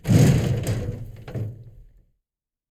Soundscape Overhaul / gamedata / sounds / ambient / soundscape / underground / under_10.ogg